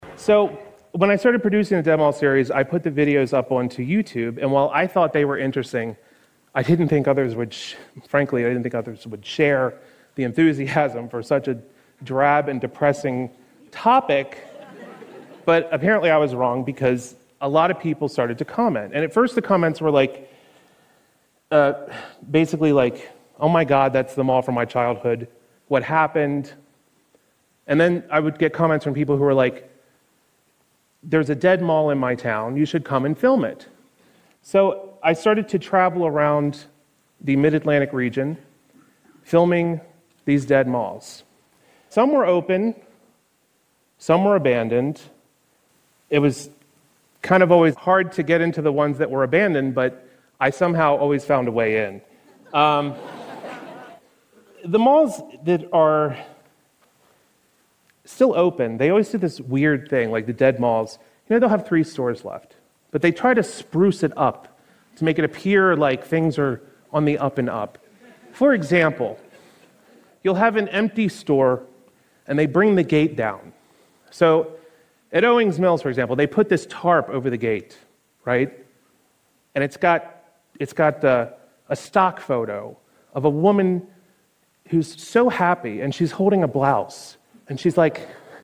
TED演讲:美国废弃的购物中心(3) 听力文件下载—在线英语听力室